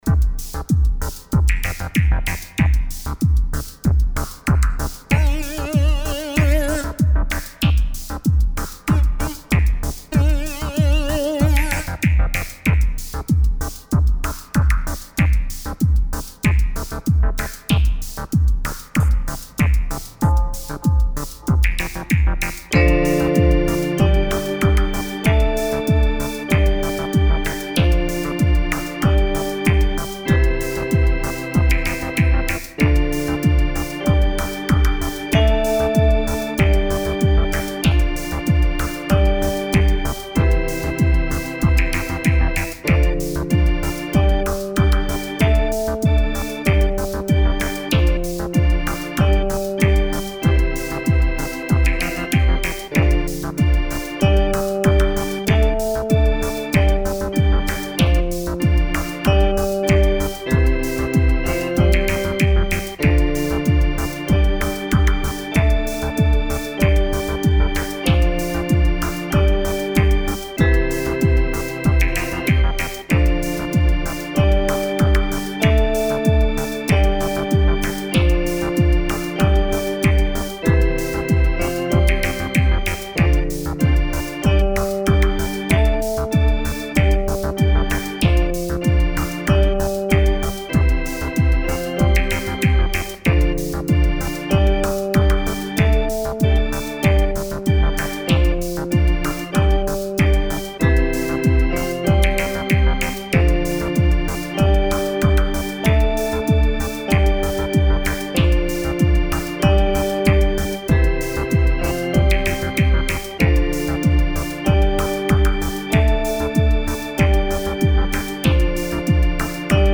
Party, Disco Film.